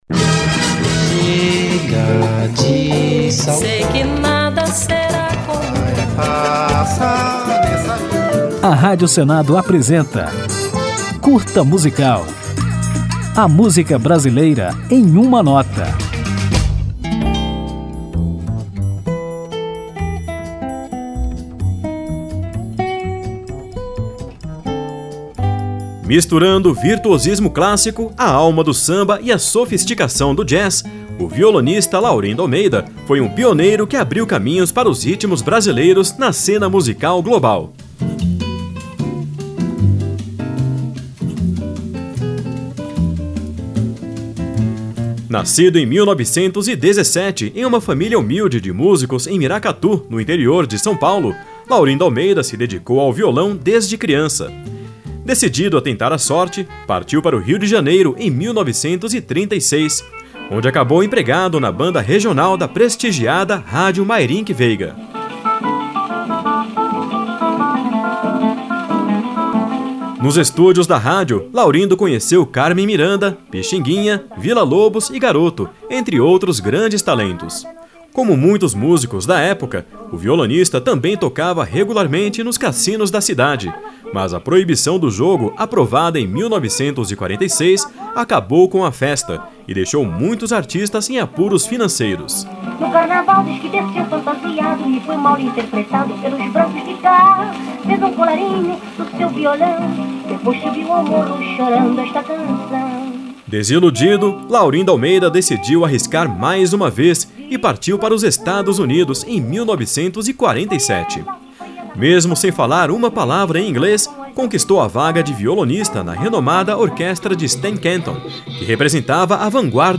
Nesse Curta Musical, você vai conhecer um pouco desse instrumentista excepcional pouco conhecido no Brasil, apesar do enorme reconhecimento no exterior. Ao final, ouviremos a música Terra Seca, em que o violonista é acompanhado pelo saxofonista Bud Shank.
Jazz